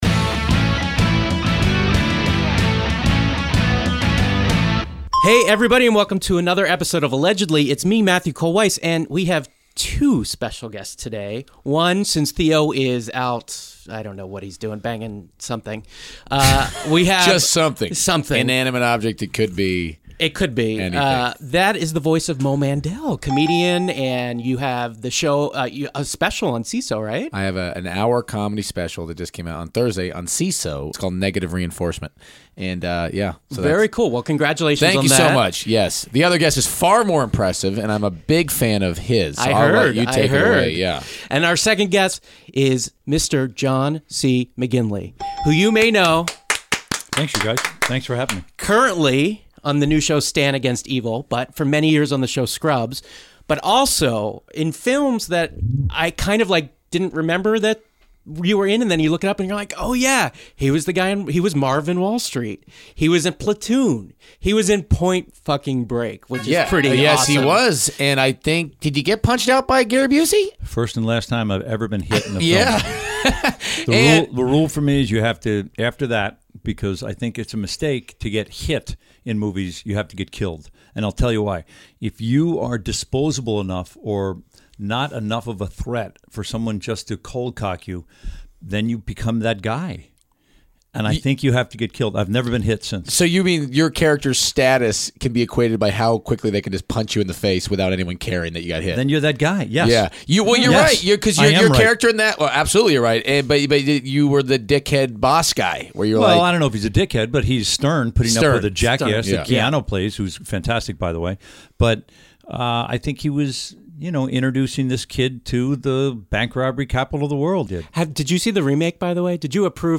The former "Scrubs" star and current "Stan Against Evil" anti-hero joins the show to discuss his wild career... who is on his shit list in Hollywood (he's looking at you Ben Stiller)... and what makes an Irish Jew tick.